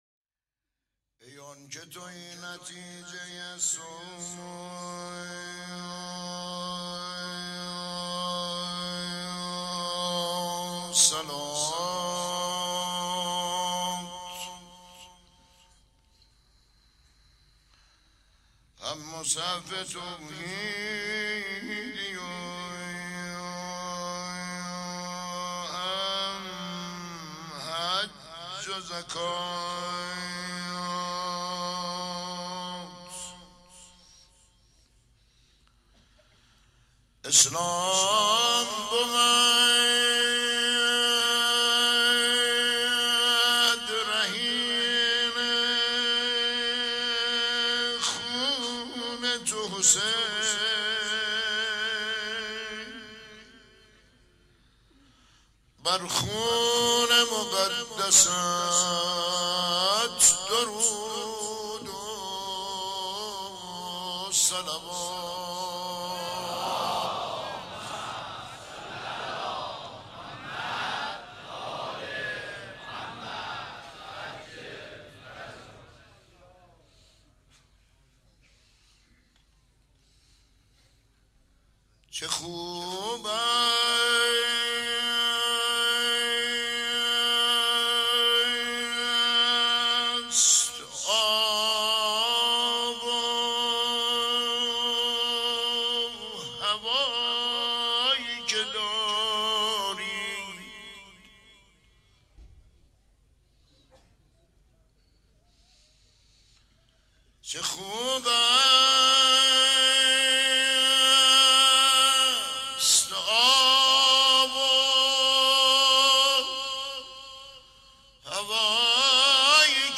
مناسبت : شب سوم محرم
قالب : روضه